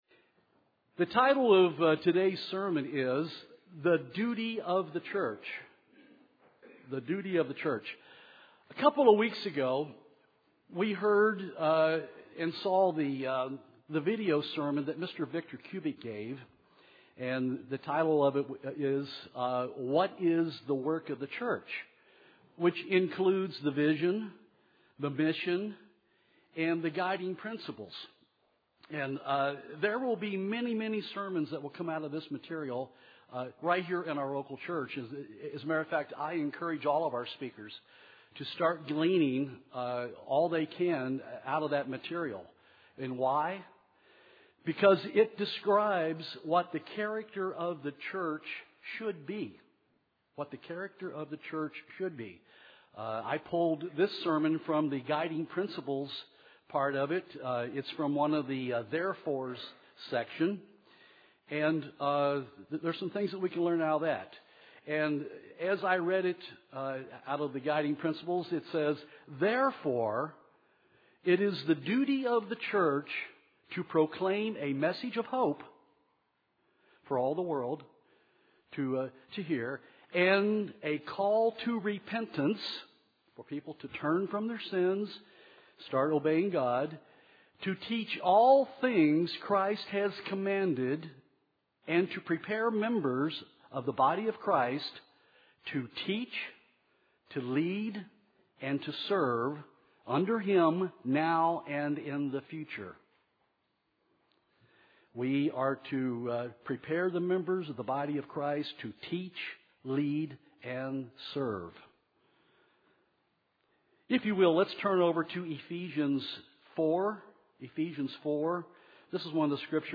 Given in Nashville, TN
Related reading: The Church Jesus Built UCG Sermon Studying the bible?